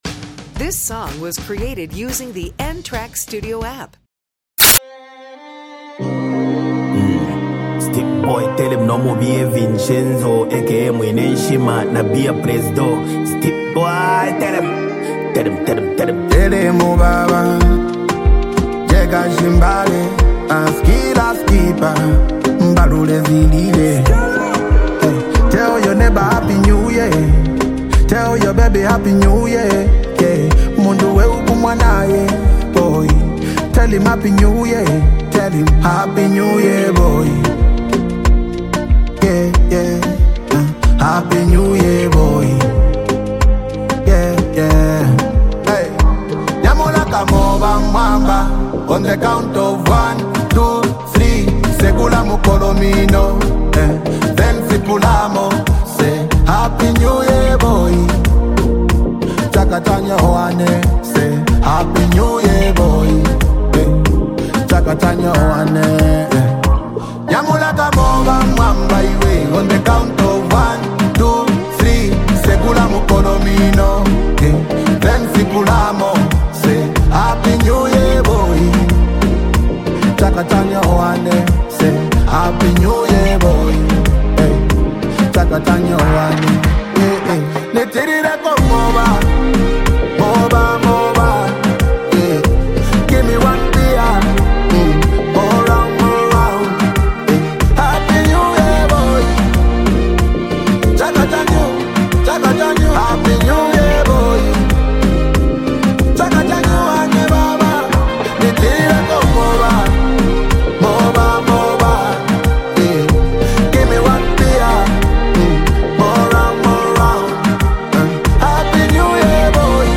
celebratory anthem
using his smooth vocals to emphasize gratitude
reflective yet confident verses